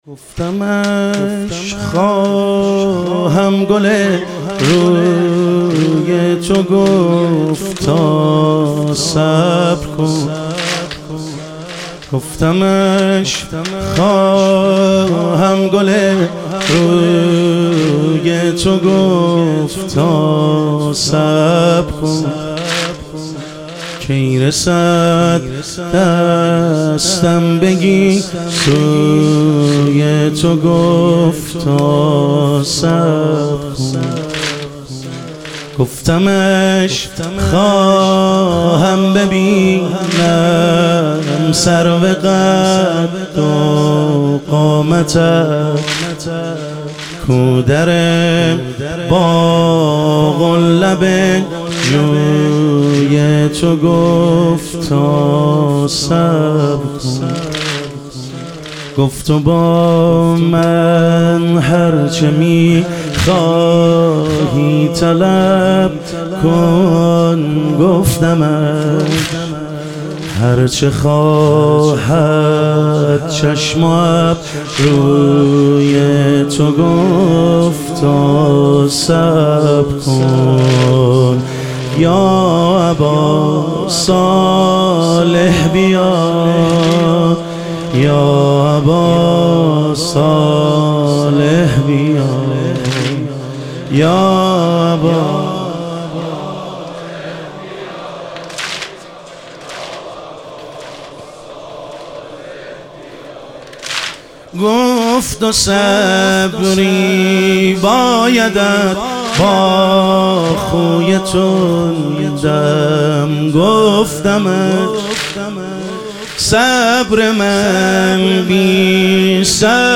لیالی قدر و شهادت امیرالمومنین علیه السلام - واحد